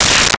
Space Gun 06
Space Gun 06.wav